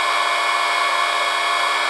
avionics_3.wav